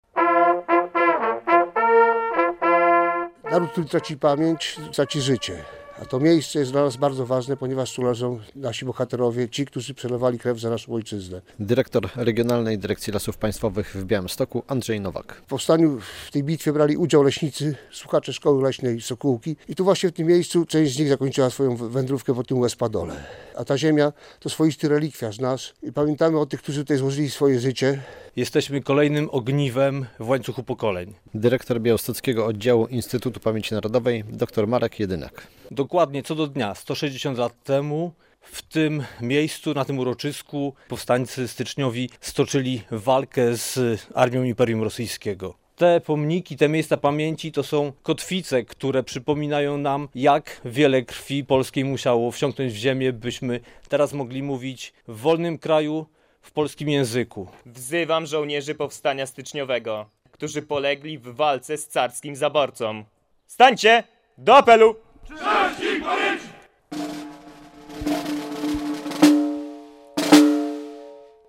Obchody 160 rocznicy Bitwy pod Waliłami - relacja